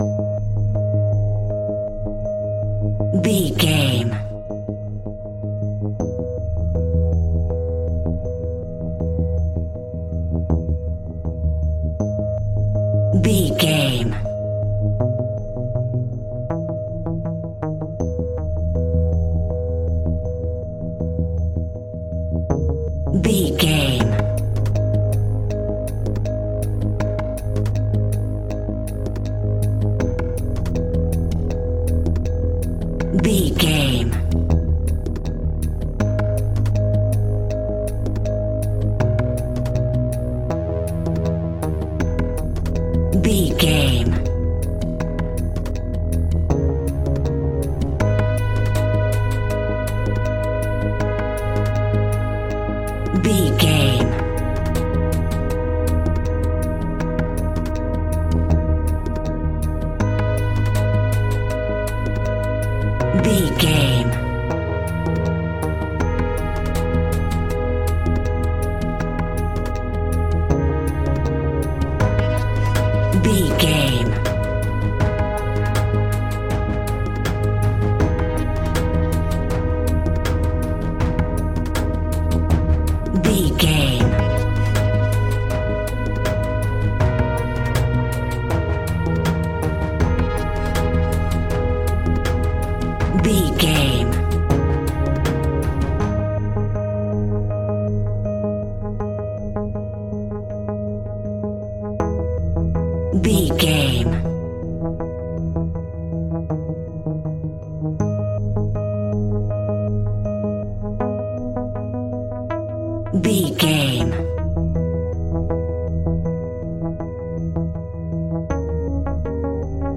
Aeolian/Minor
G#
ominous
dark
eerie
electric piano
percussion
drums
synthesiser
strings
horror music